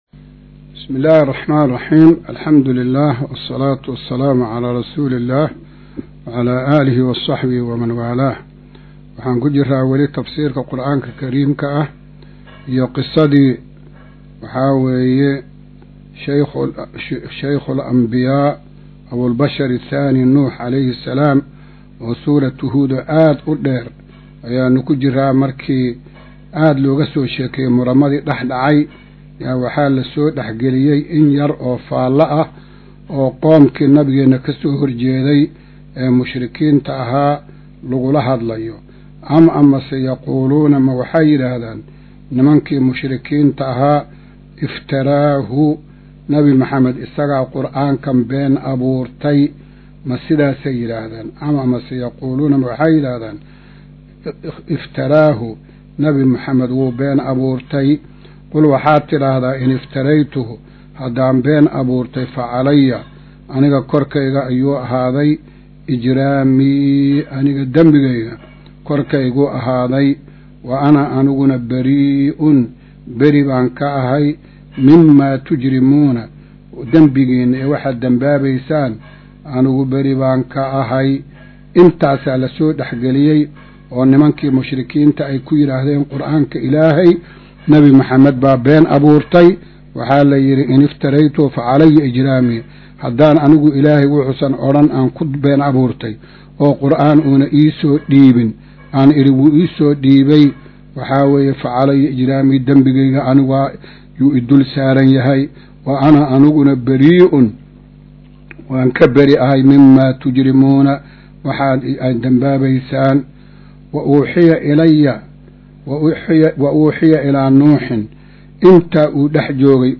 Maqal:- Casharka Tafsiirka Qur’aanka Idaacadda Himilo “Darsiga 112aad”